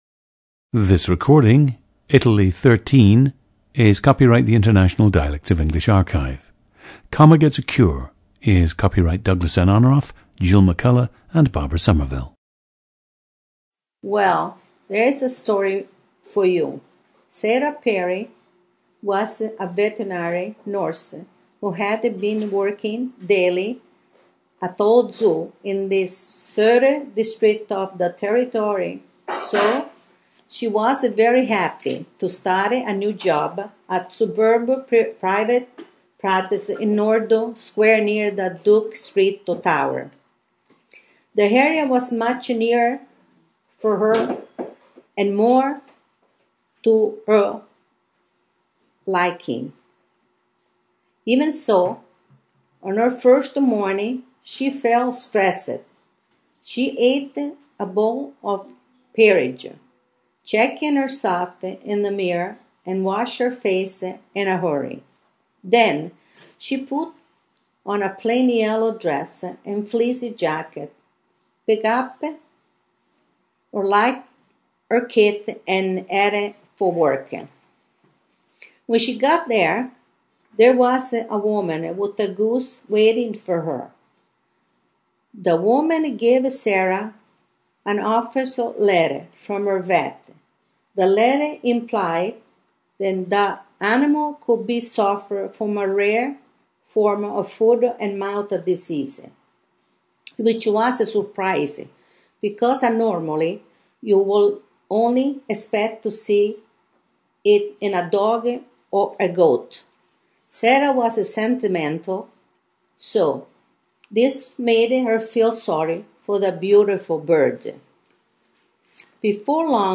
GENDER: female
ETHNICITY: Italian/Sicilian
Despite living most of her life in the United States, her accent is still strong.
• Recordings of accent/dialect speakers from the region you select.
The recordings average four minutes in length and feature both the reading of one of two standard passages, and some unscripted speech.